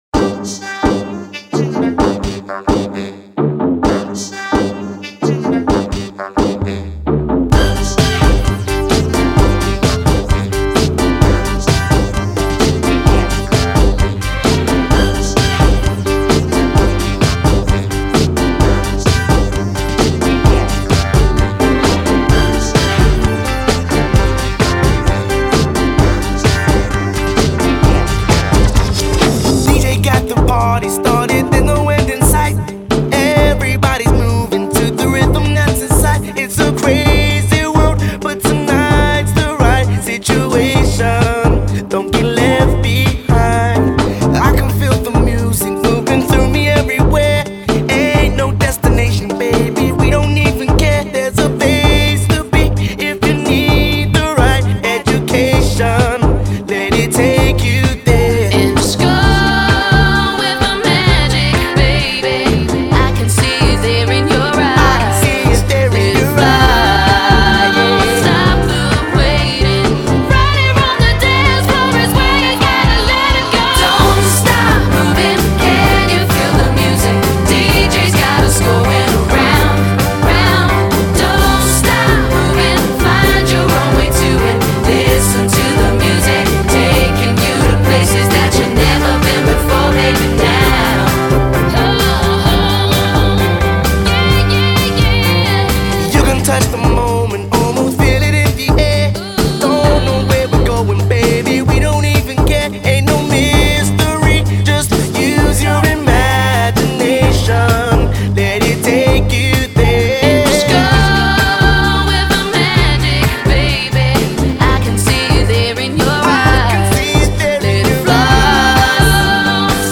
Acapella
Instrumentale